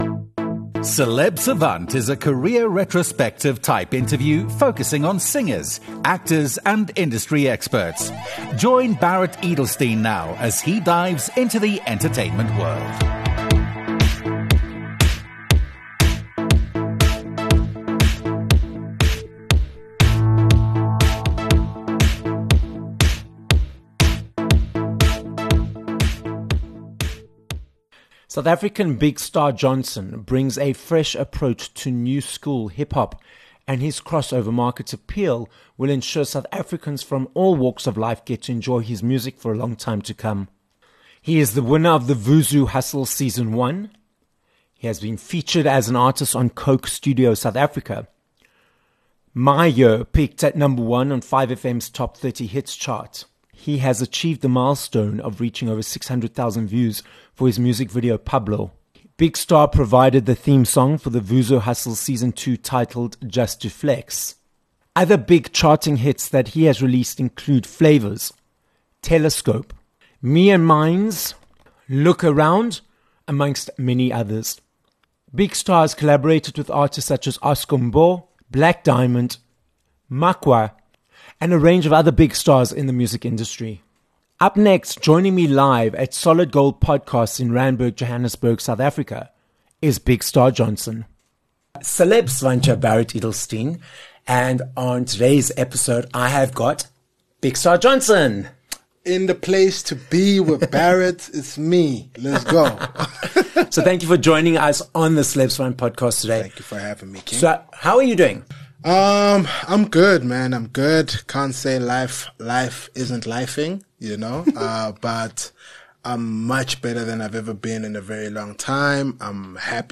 Interview
This episode of Celeb Savant was recorded live in studio at Solid Gold Podcasts in Randburg, Johannesburg, South Africa.